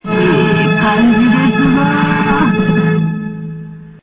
そして声でも玉緒さんがゲームを盛り上げます。
青い文字、またはスピーカーアイコンをクリックするとRealAudioで玉緒さんの声を聞くことができます。